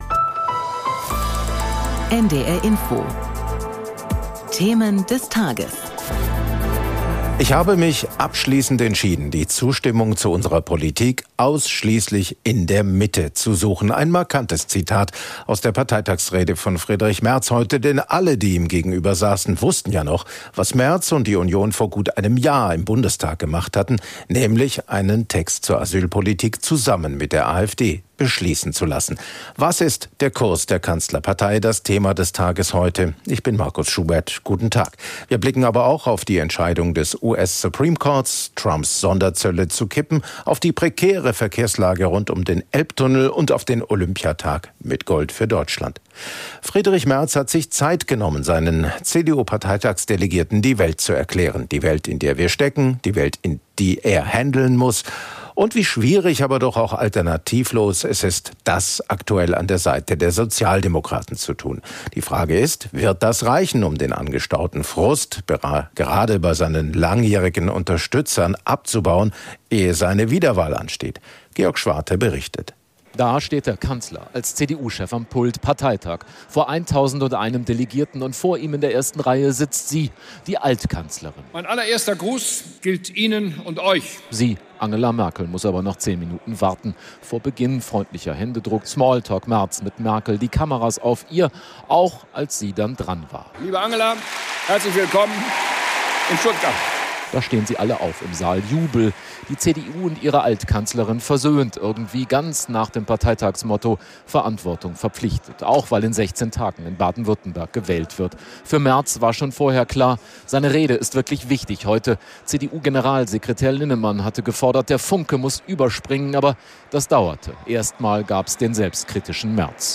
Merz-Rede auf dem CDU-Parteitag